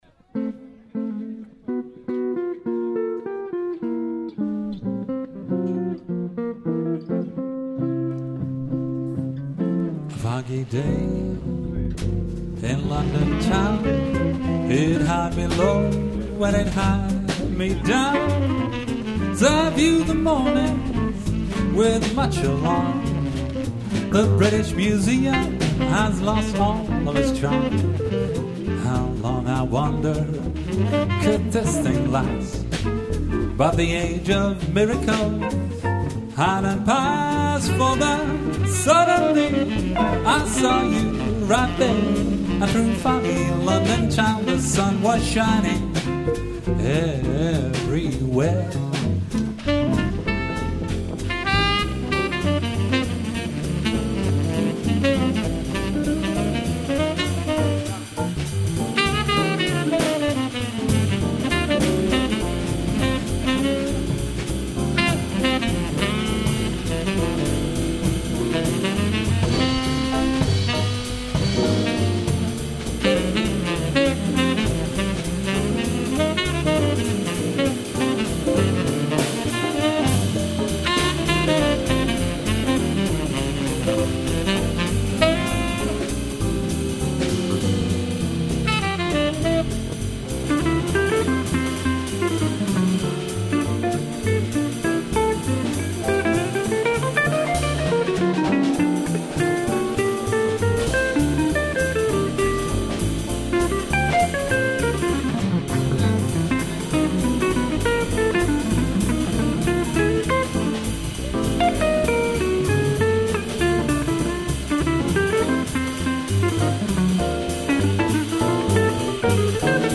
Zang
Trompet
Tenorsax
Gitaar
Piano
Drums